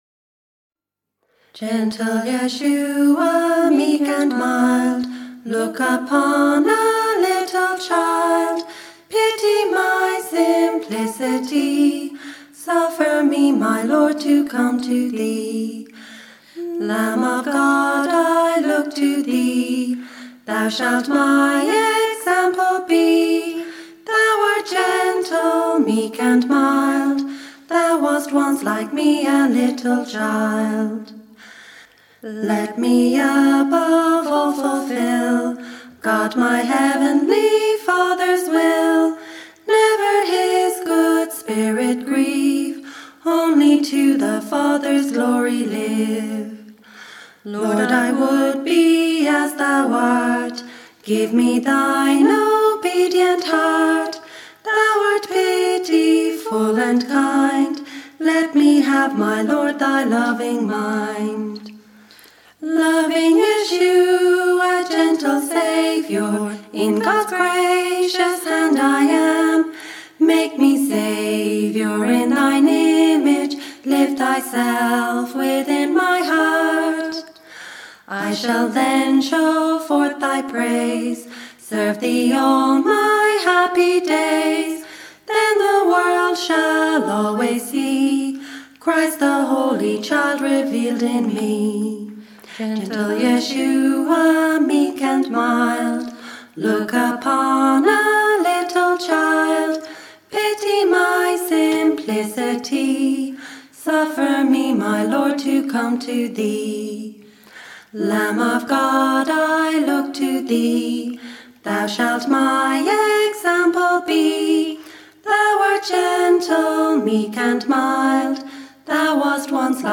Charles Wesley song